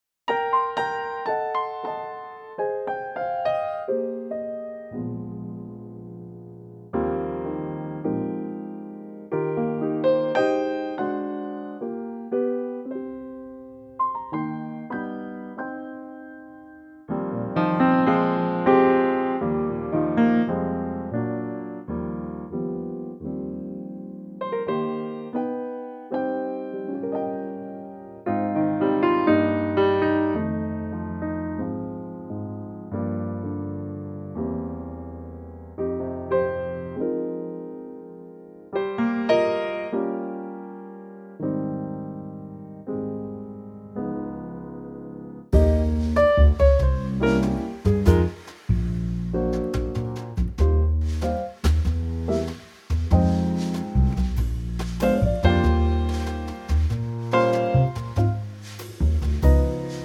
Unique Backing Tracks
key - Eb - vocal range - Eb to Ab